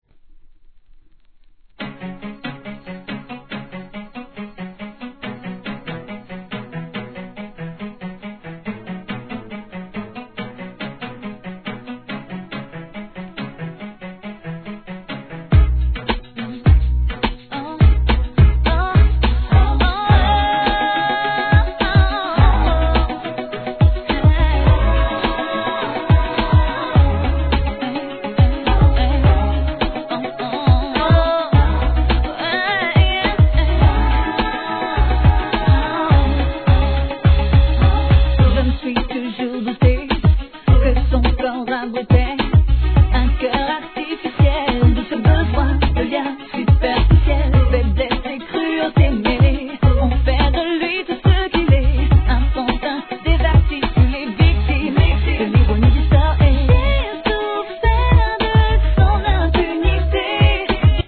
HIP HOP/R&B
US直球なキャッチーさでのフランス産R&B!